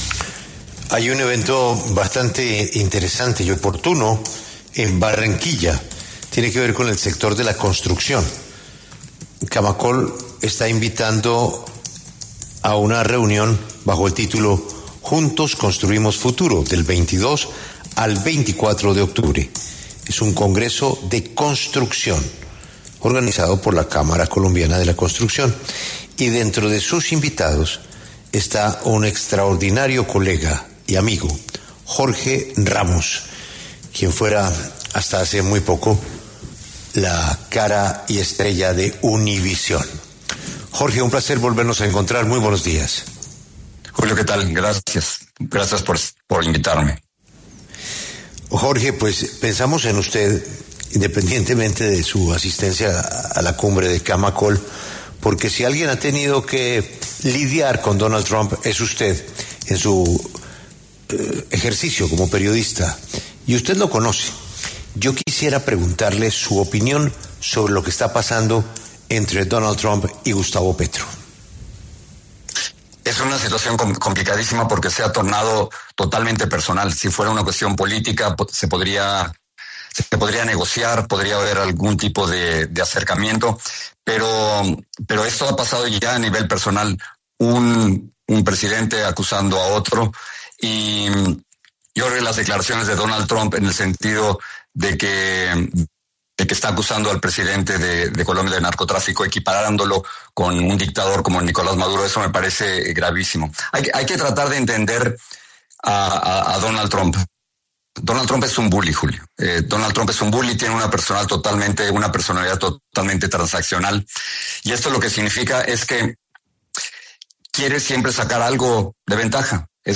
El expresentador de Univisión, Jorge Ramos, conocido como ‘La voz de los Latinos’, conversó con La W sobre la tensión que se vive entre los Gobiernos de Donald Trump y Gustavo Petro.